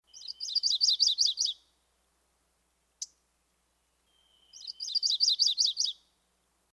MacGillivrays_Warbler
MacGillivrays_Warbler.mp3